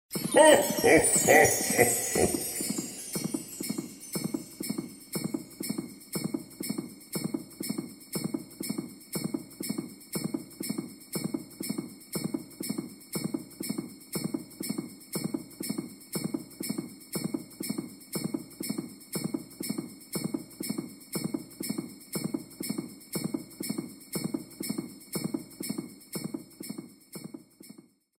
Звук приближающегося Санта Клауса